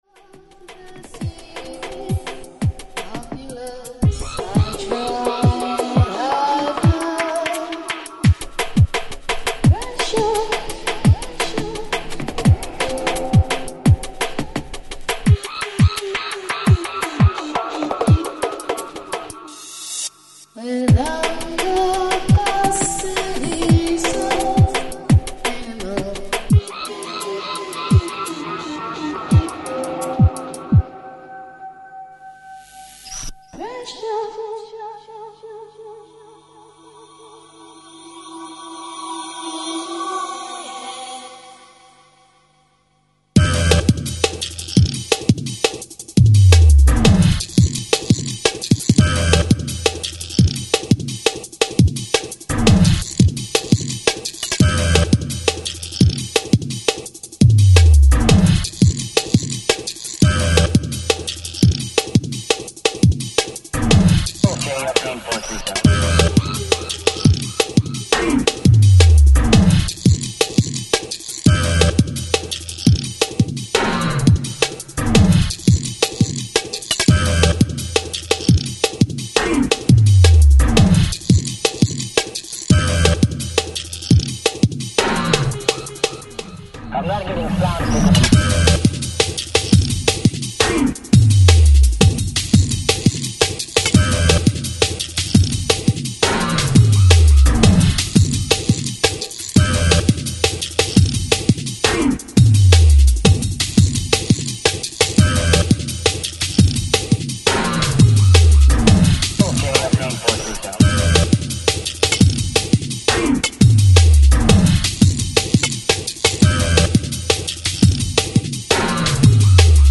futuristic dark sounds and the early Techstep scene in DNB